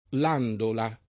[ l # ndola ]